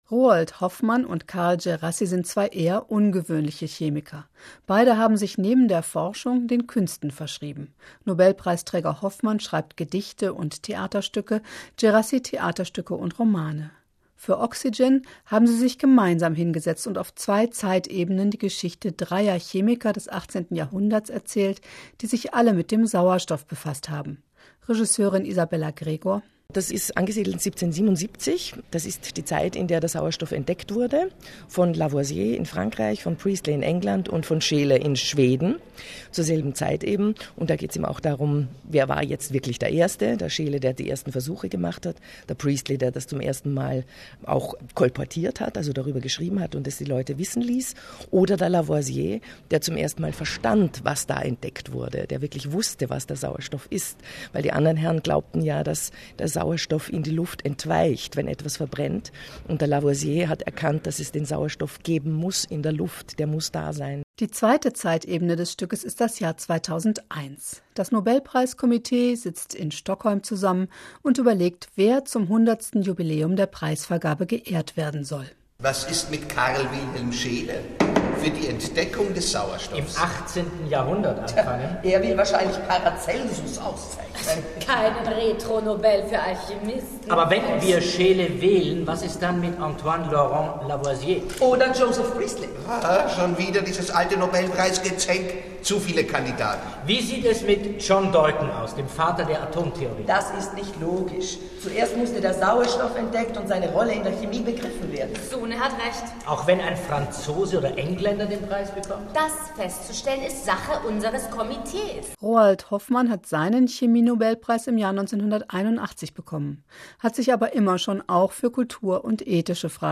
Weitere Dateien zu dem Radiobeitrag über das Theaterstück im rbb Kultradio Programm des rbb Kultradios (PDF)Tonmitschnitt des Radiobeitrags (MP3)